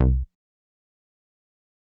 Bass (sdp interlude).wav